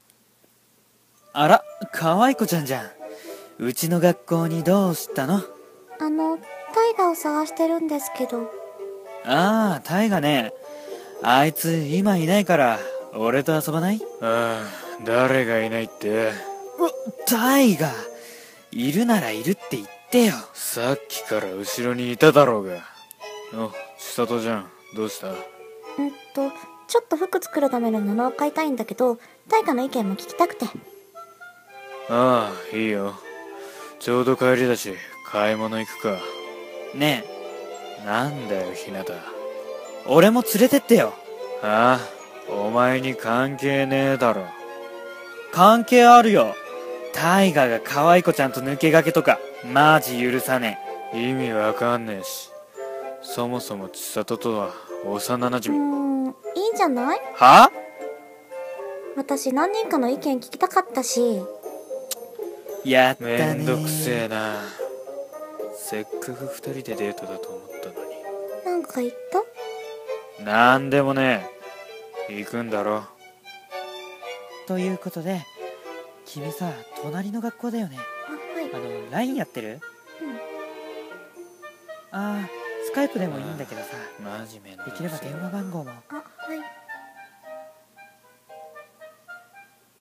【声劇】帰り〜w《コラボ用》